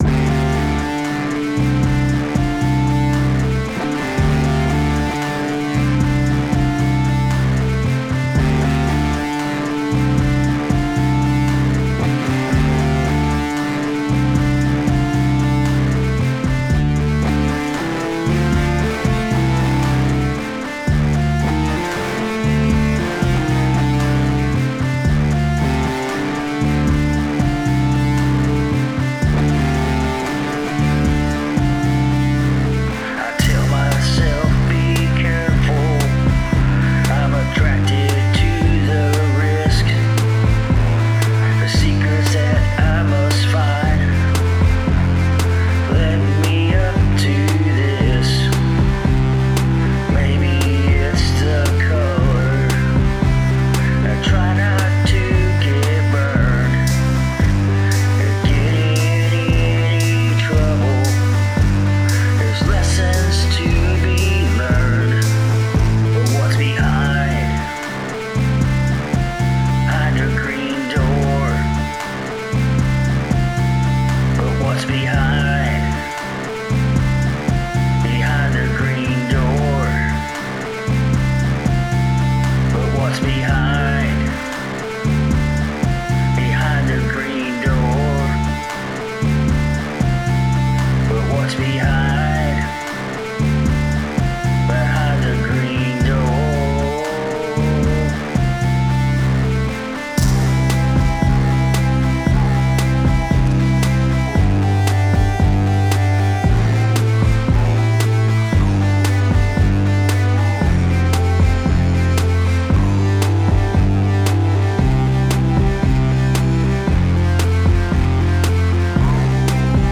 I sound so nervous, like this is my first FAWM or something.
Your vocals are very alluring too!